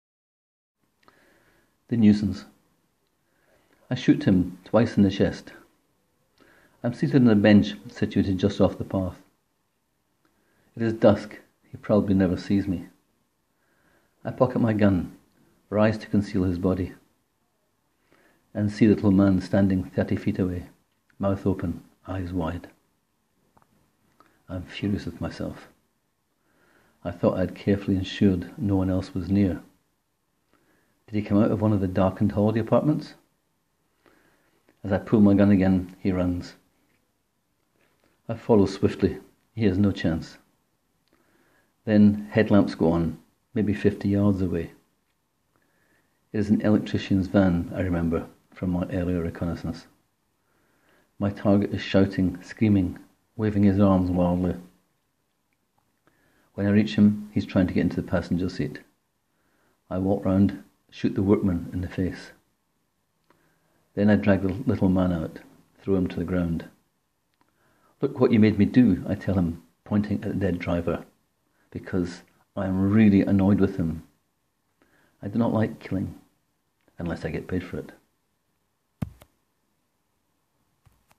More mundanely, I talk into my phone, transfer to my laptop, convert mp4 to mp3, et voila!
Straight dark comedy.